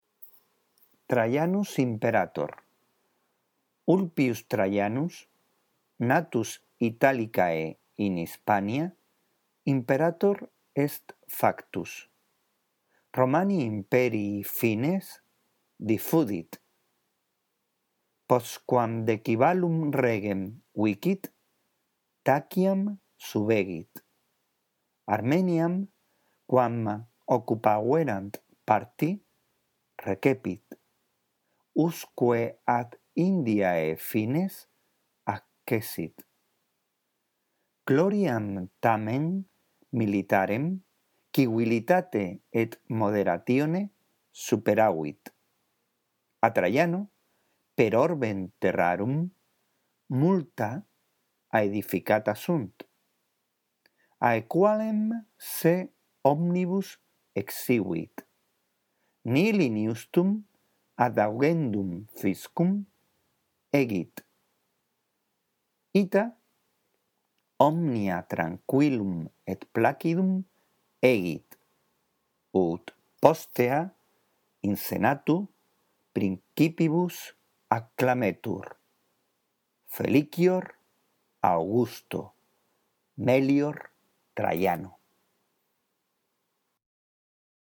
La audición de este archivo contribuirá a que mejores tu lectura del latín: